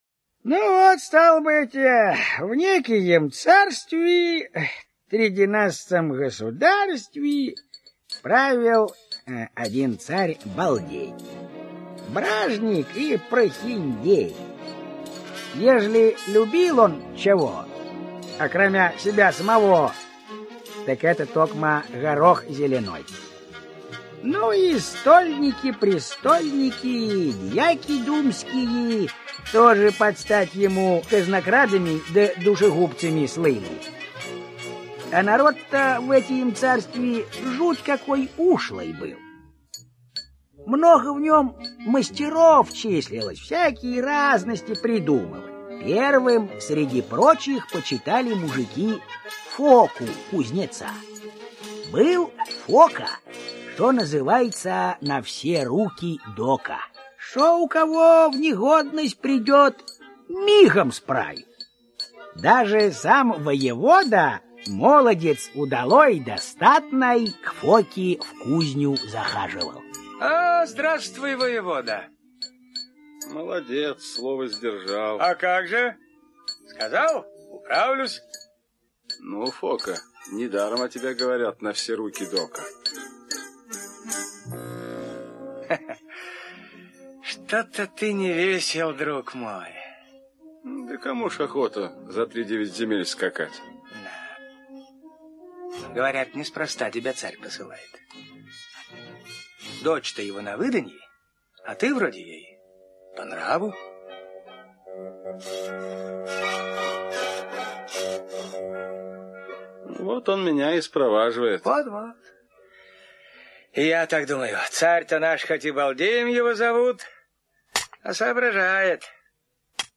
Фока-на все руки дока - аудиосказка Пермяка - слушать скачать